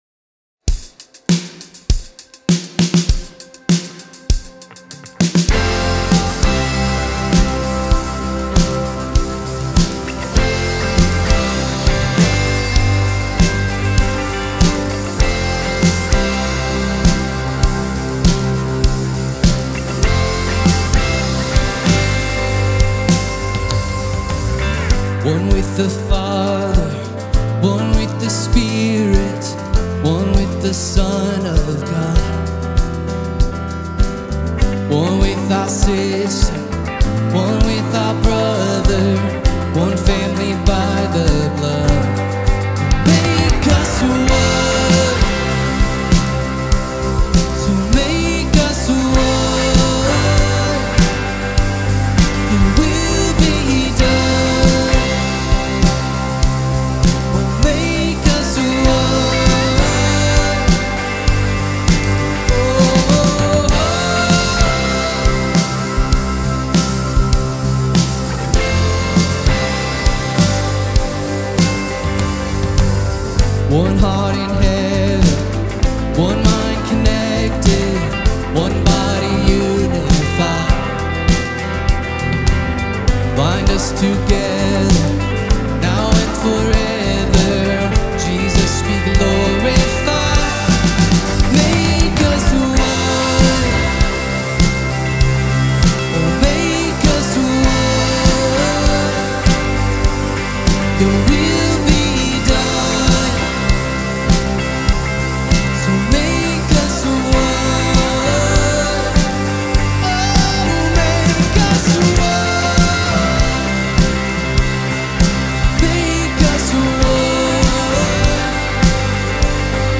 Here are some of the musical adventures of the worship team that I've mixed in post-production